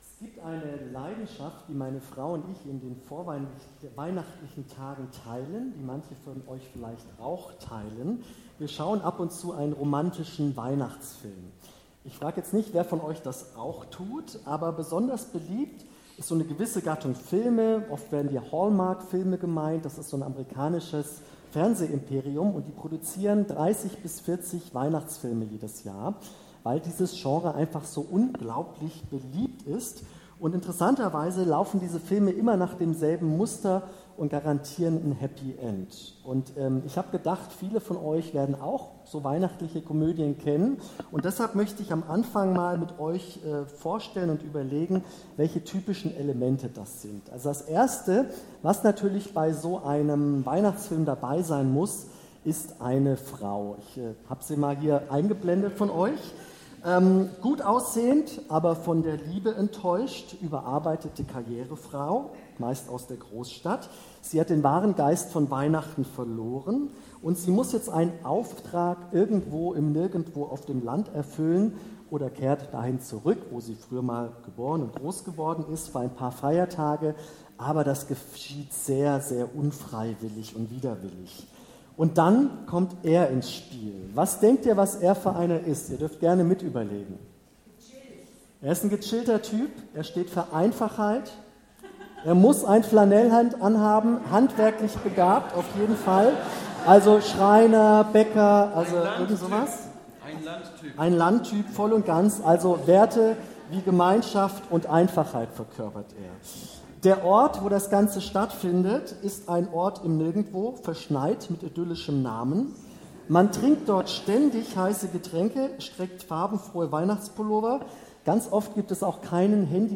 Predigt am Sonntag